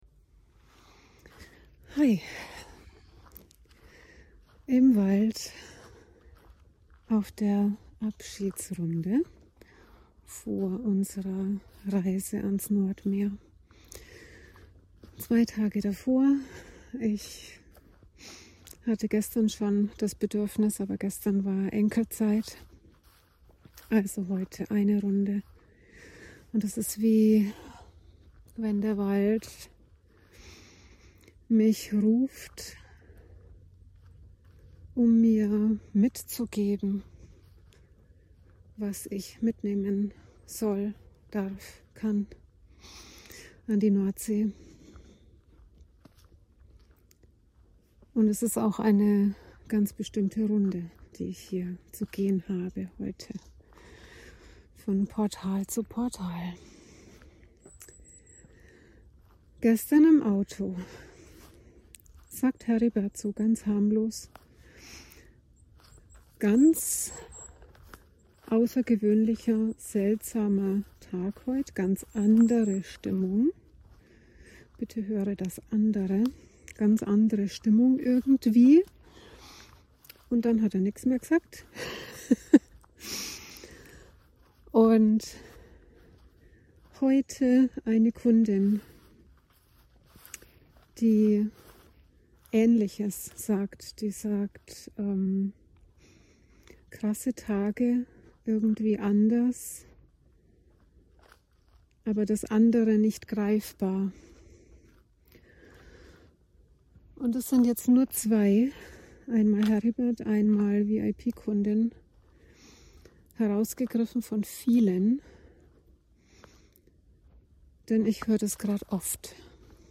Davon auf meiner AbschiedsRunde durch den KeltenWald vor der Reise ans NordMeer.